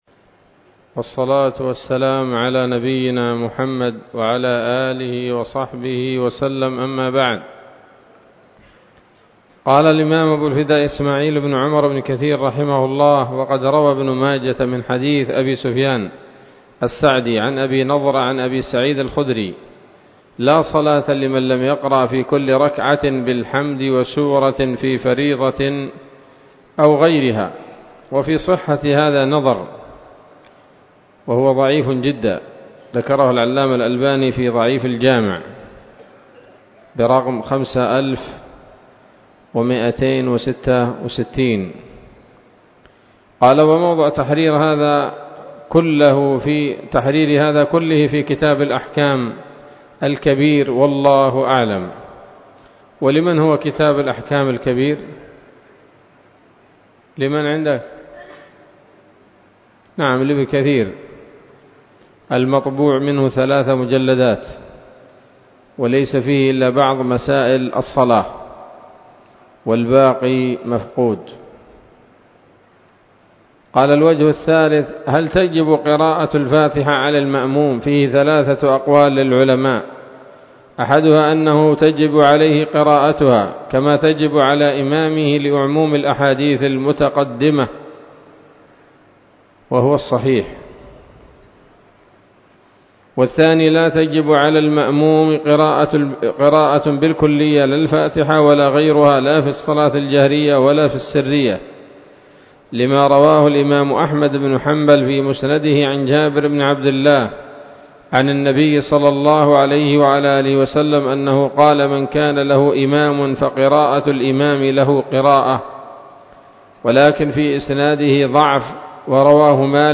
الدرس الخامس من سورة الفاتحة من تفسير ابن كثير رحمه الله تعالى